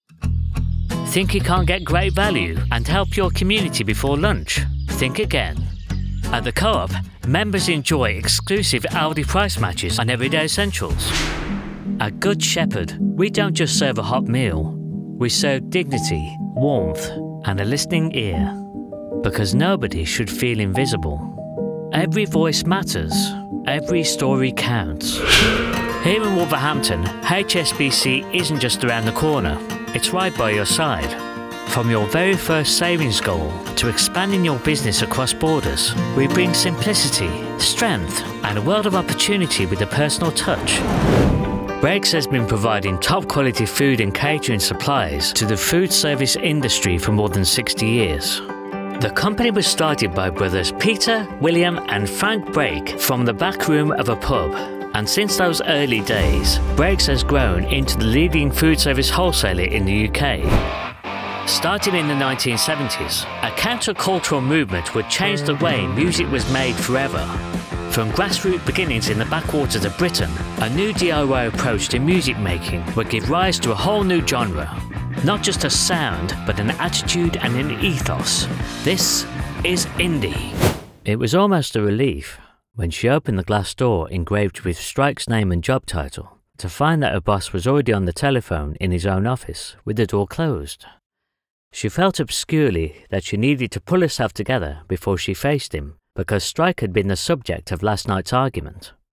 British Radio & TV Commercial Voice Overs Artists
Adult (30-50)